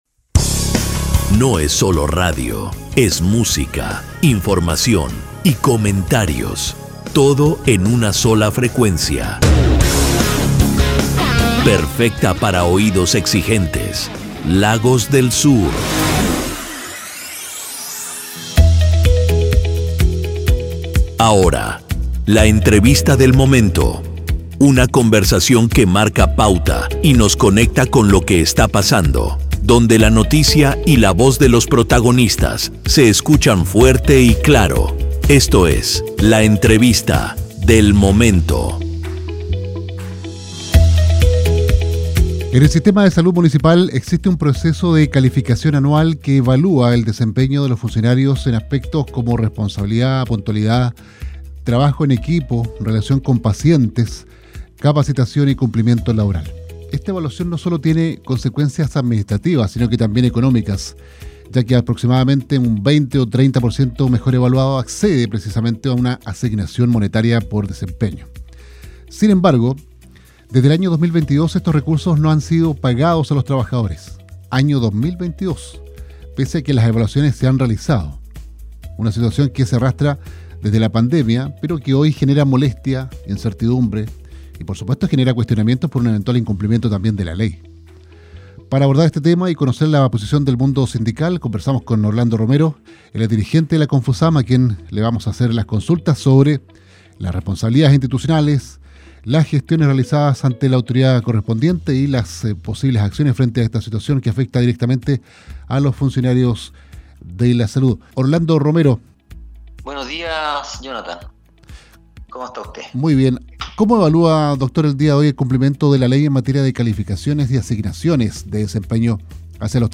Entrevista-salud-calificaciones-deuda.mp3